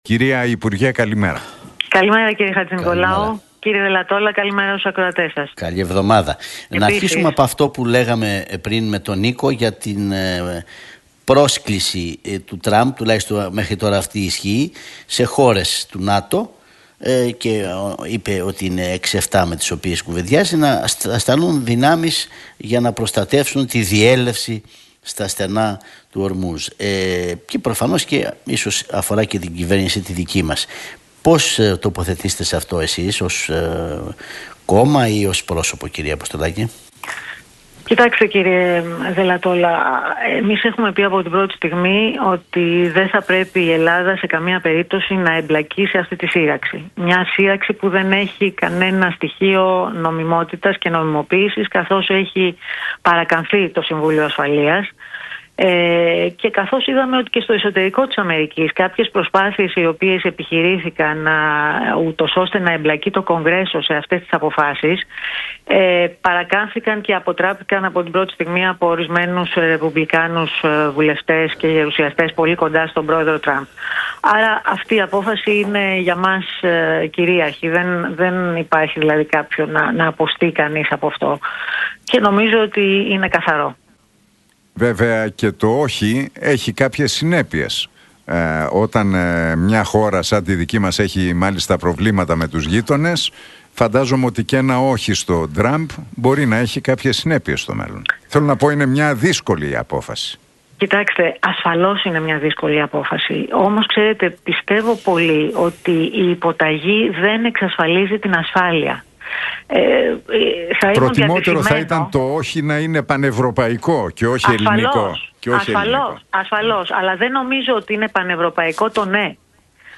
Για το συνέδριο του ΠΑΣΟΚ, τη διαγραφή του Οδυσσέα Κωνσταντινόπουλου, τον στόχο του κόμματος στις εθνικές εκλογές αλλά και για τις διεθνείς εξελίξεις μίλησε η Μιλένα Αποστολάκη στον Realfm 97,8